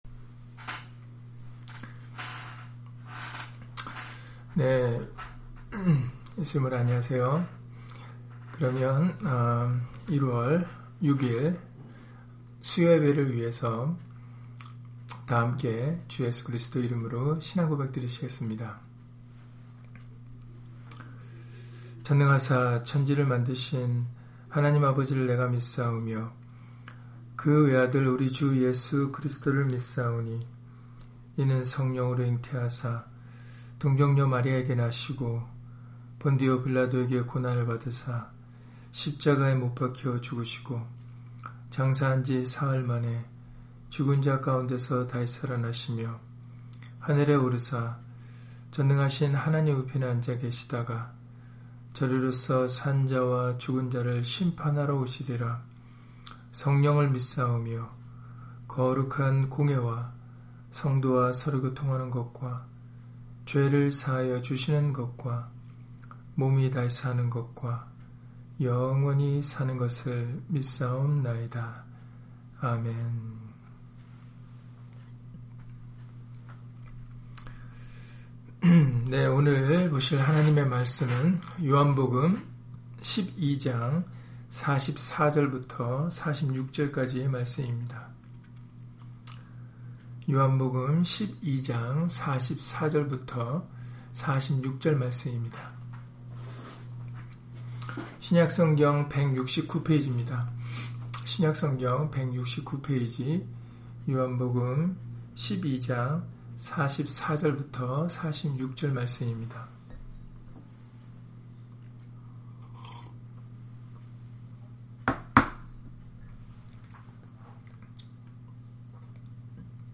요한복음 12장 44-46절 [나는 빛으로 세상에 왔나니] - 주일/수요예배 설교 - 주 예수 그리스도 이름 예배당